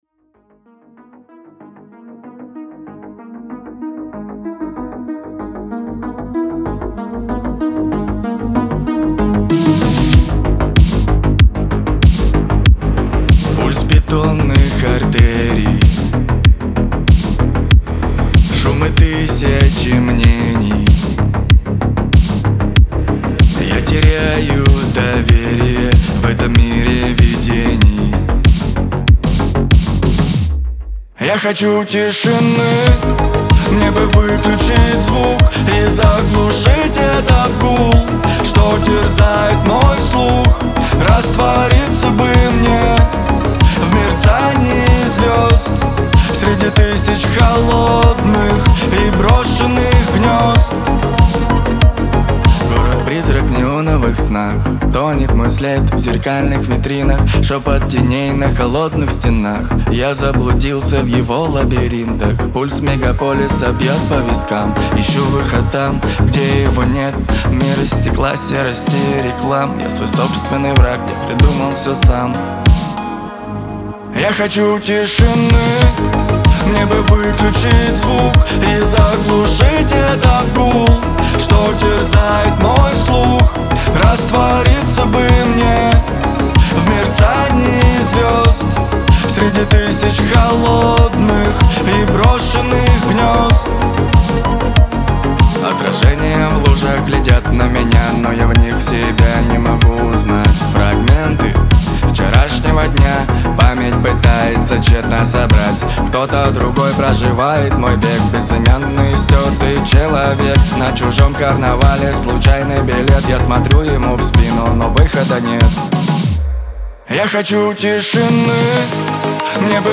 Лирика
эстрада